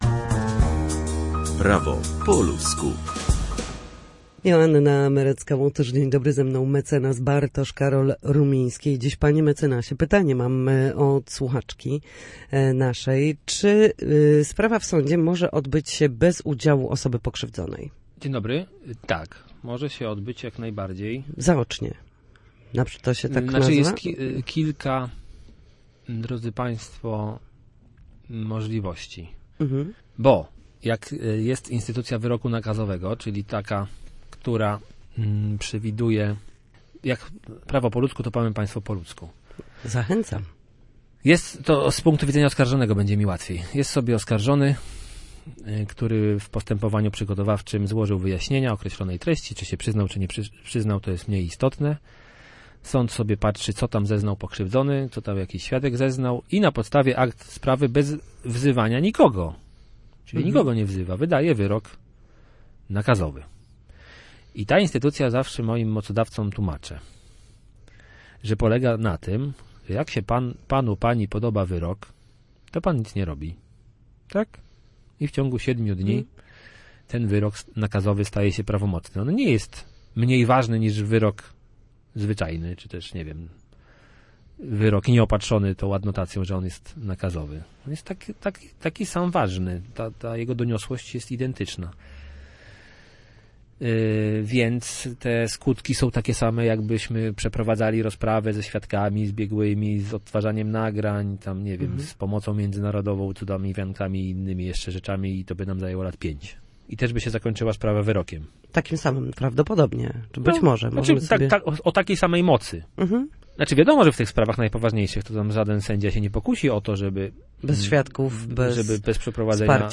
W każdy wtorek o godzinie 13:40 na antenie Studia Słupsk przybliżamy państwu meandry prawa.